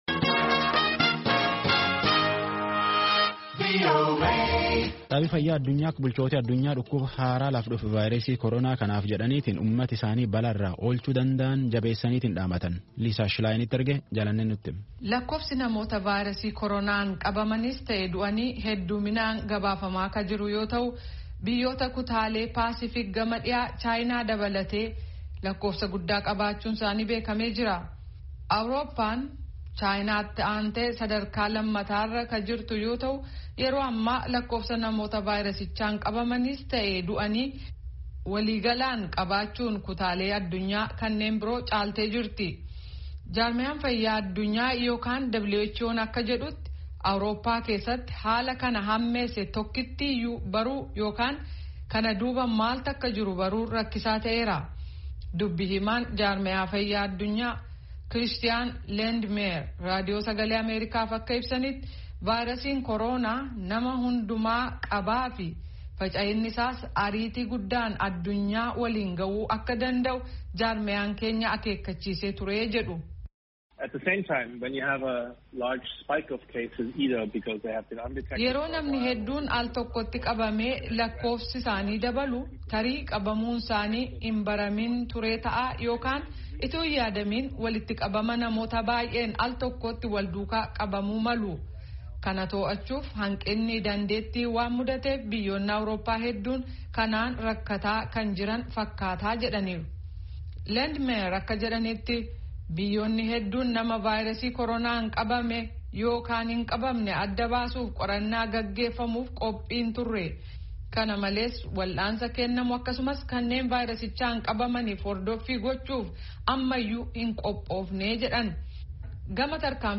Gabaasa Guutuu caqasaa